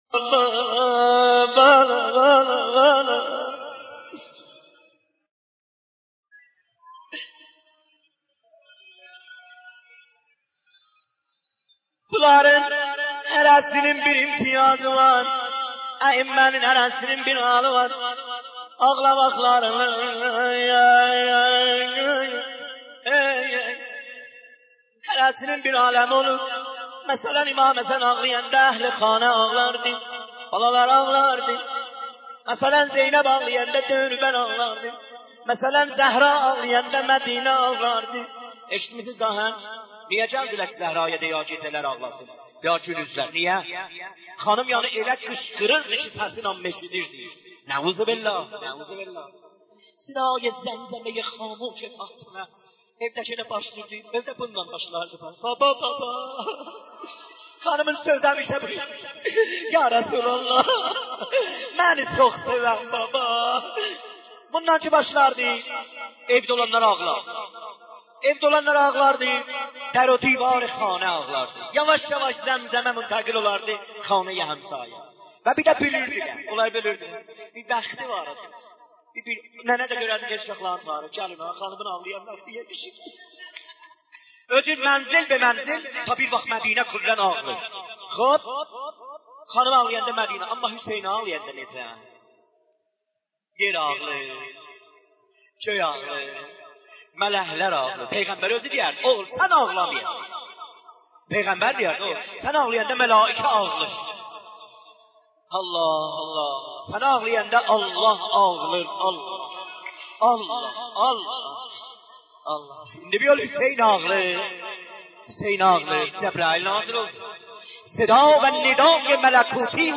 دانلود مداحی دریای صبر - دانلود ریمیکس و آهنگ جدید
مراسم روضه خوانی(آذری) به مناسبت شهادت حضرت زهرا(س)